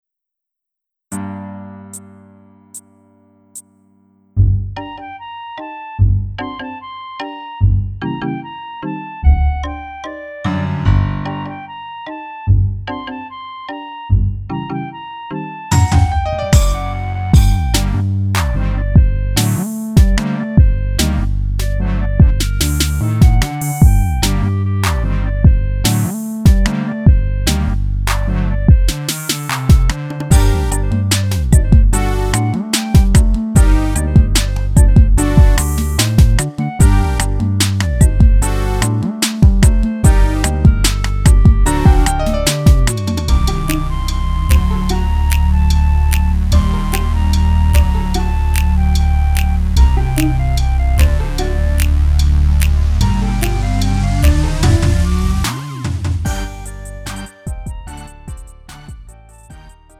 음정 -1키 3:26
장르 가요 구분 Lite MR